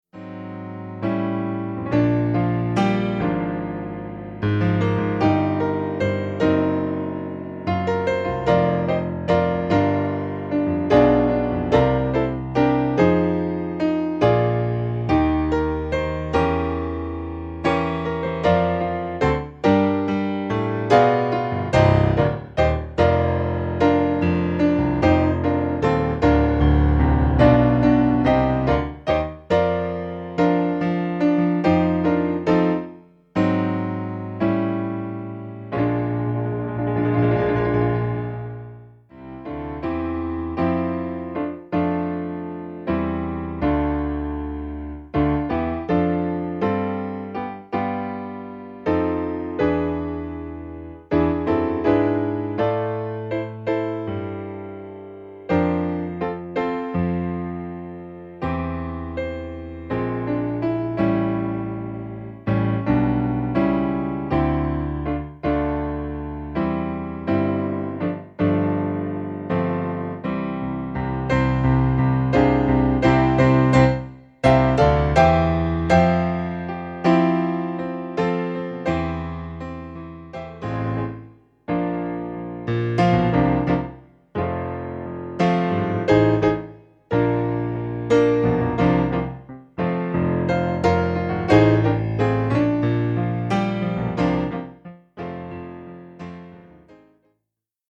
Besetzung: Klavier solo